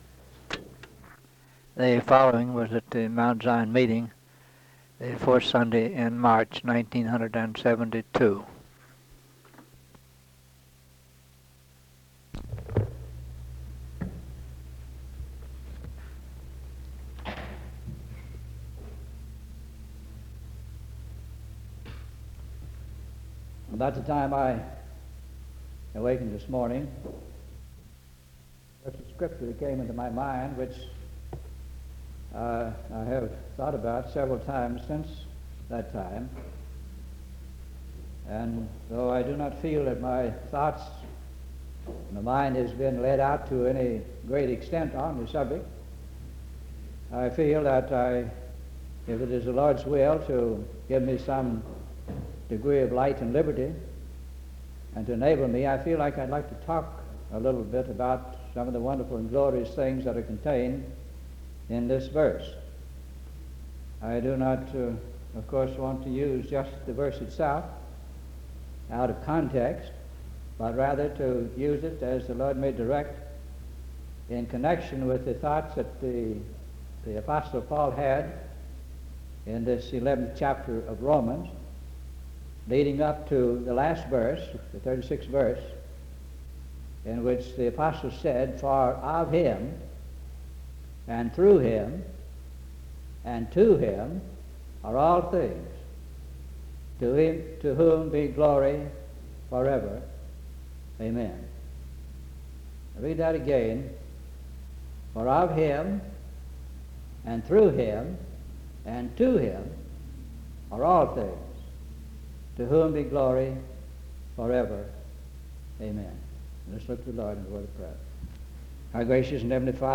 sermon collection